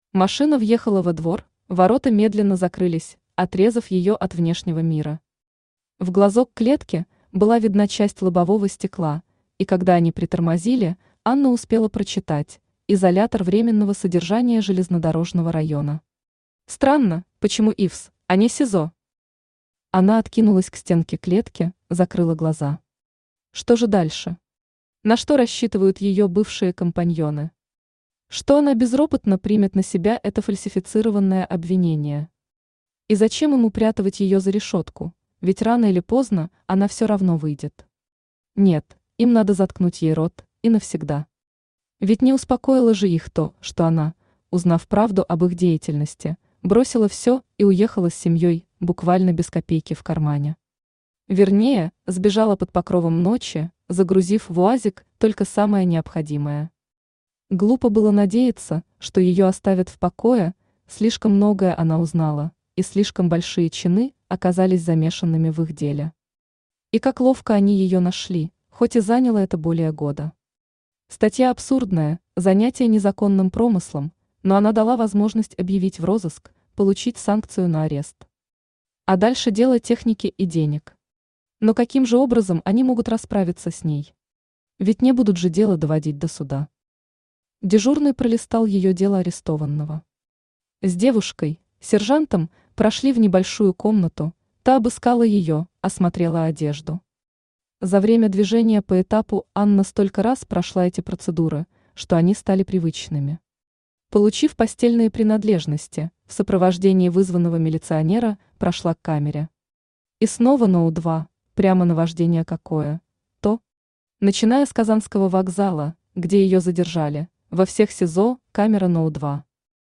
Аудиокнига Новый путь | Библиотека аудиокниг
Aудиокнига Новый путь Автор Нина Захарина Читает аудиокнигу Авточтец ЛитРес.